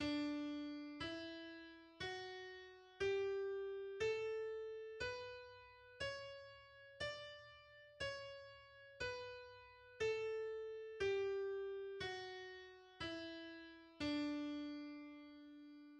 D major is a major scale based on D, consisting of the pitches D, E, F, G, A, B, and C. Its key signature has two sharps.
The D major scale is: